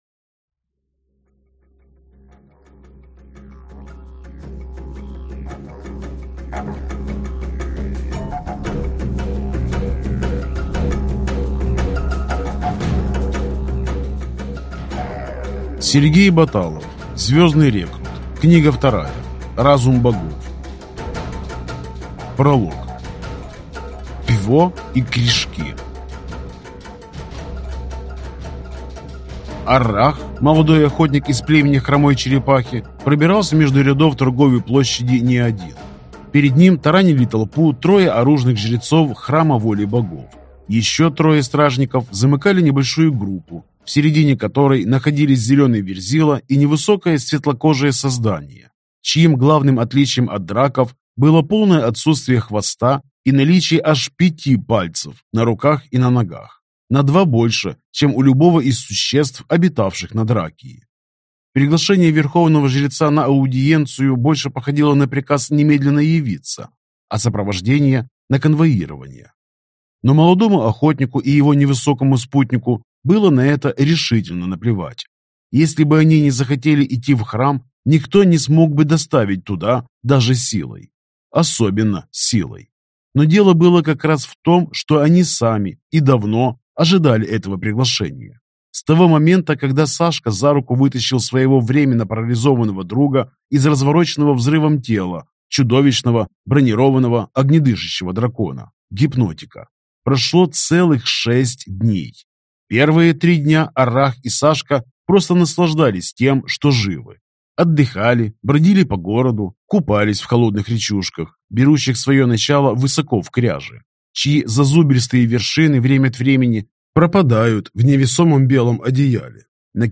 Аудиокнига Звездный рекрут. Разум богов. Книга 2 | Библиотека аудиокниг